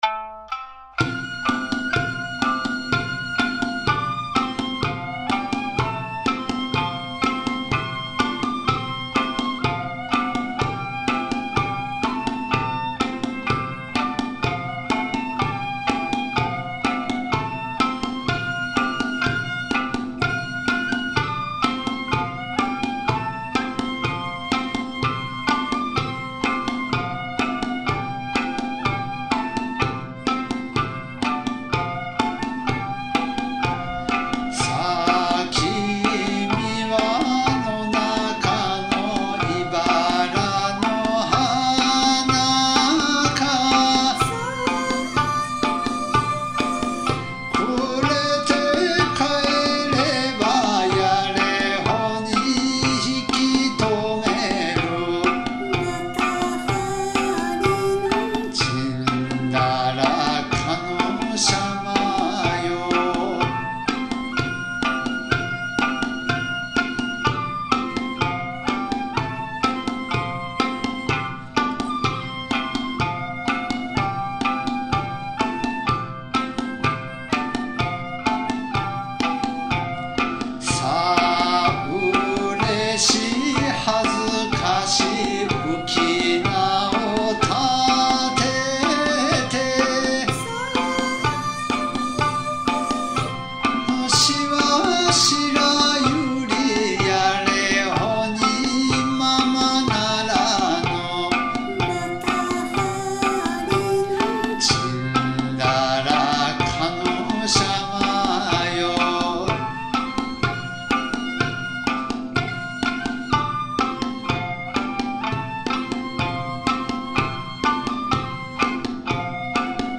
宮古民謡。
歌三線
三線　笛　太鼓　三板